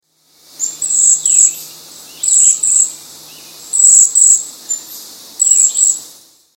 cedar-waxwing.mp3